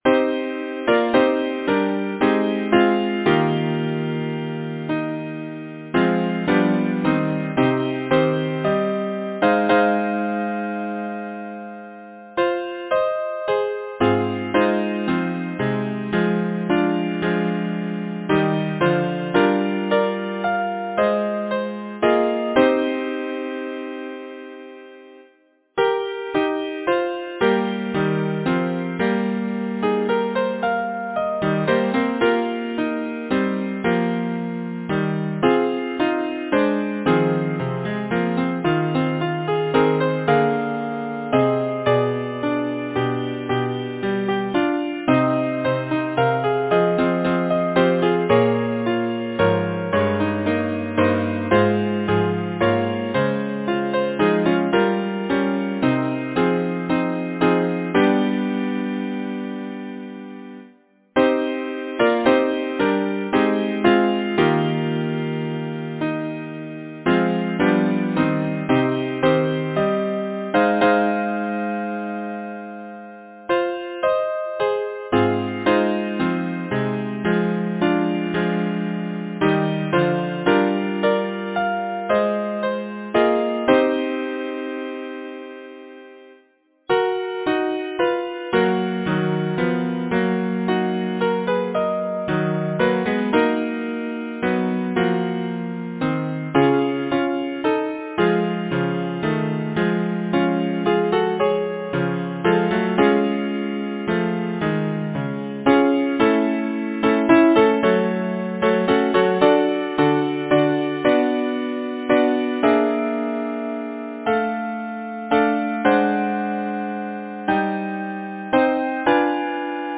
Title: From the lone Shieling Composer: Malcolm Maclean Lyricist: Anonymous Number of voices: 4vv Voicing: SATB Genre: Secular, Partsong
Language: English Instruments: A cappella